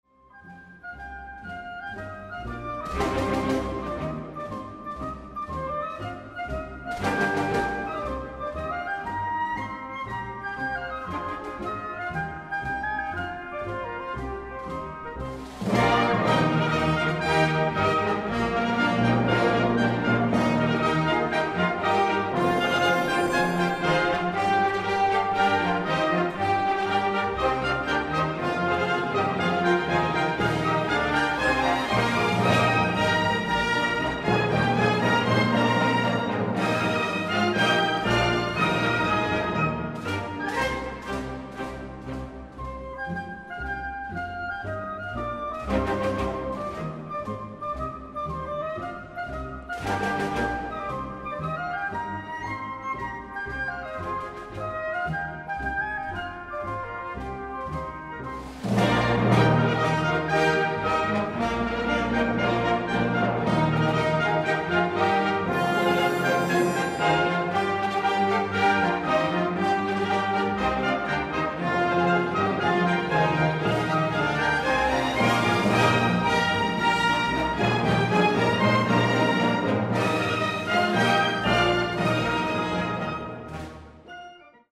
Suite “Canciones folclóricas Inglesas”Ralph Vaughan Williams